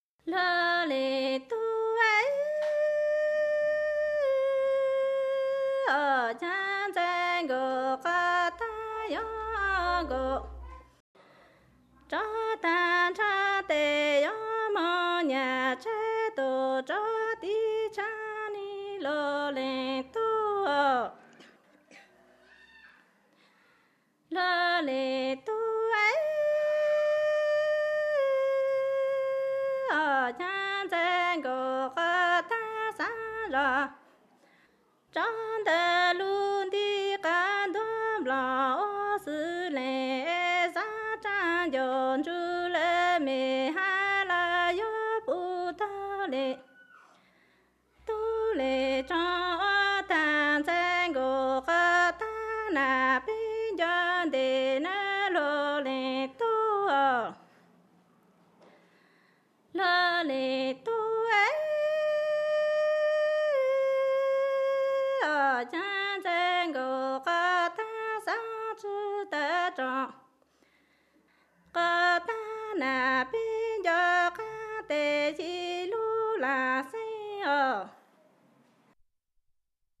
song sung by young woman the song is about going to lie with your new husband and missing your home and parents 1.2MB